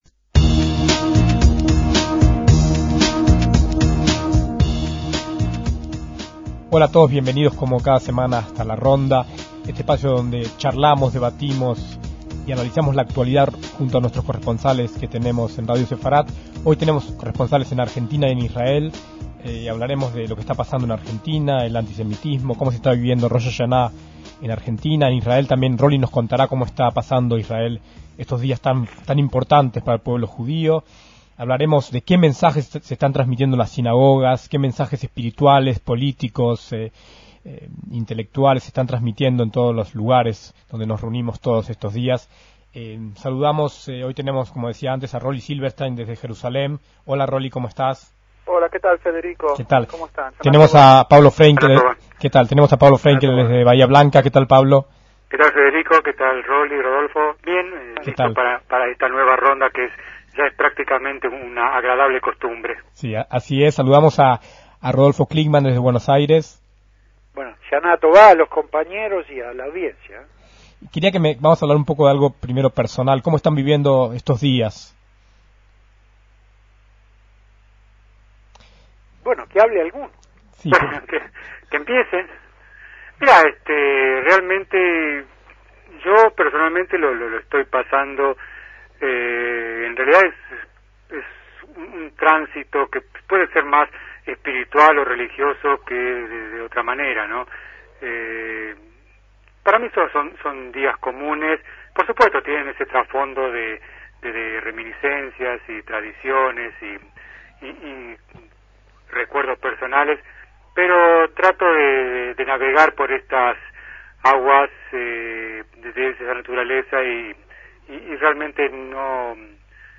Una nueva ronda de corresponsales de Radio Sefarad por el mundo